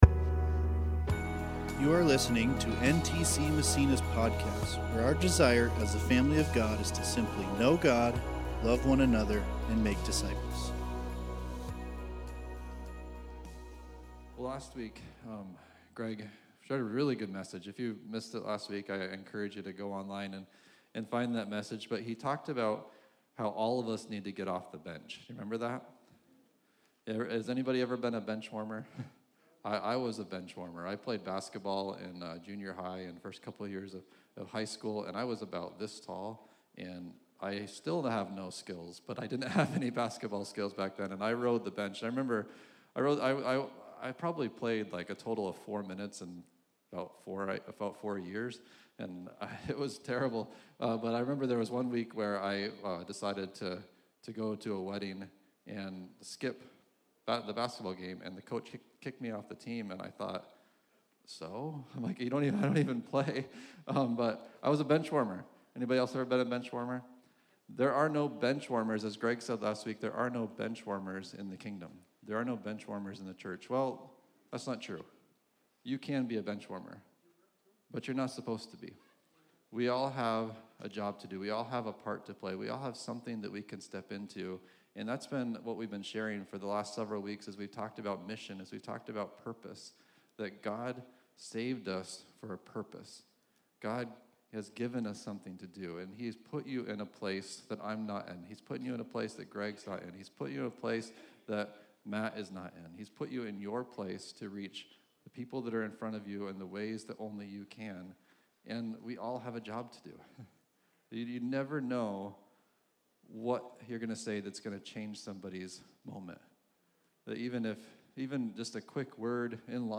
We hope you are challenged and encouraged by this message.